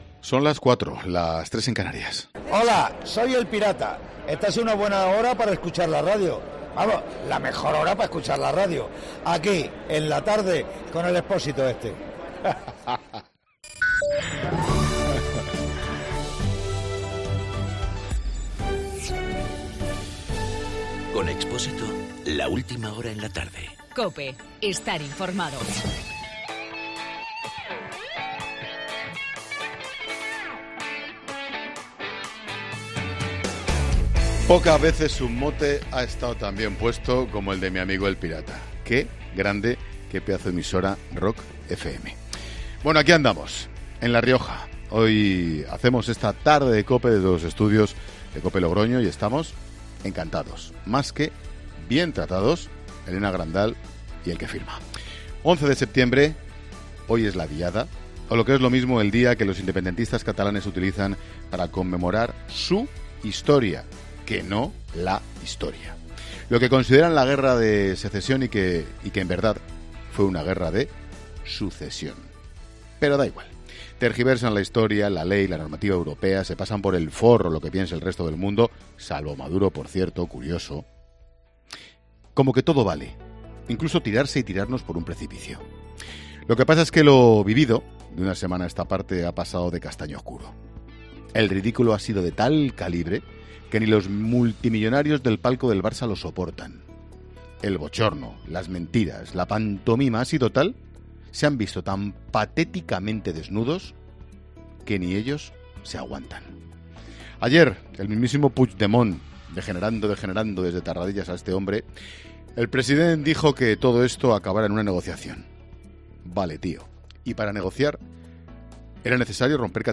AUDIO: Ángel Expósito analiza en su monólogo de las 16h los actos convocados por el Dia de Cataluña.